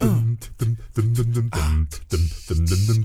ACCAPELLA10E.wav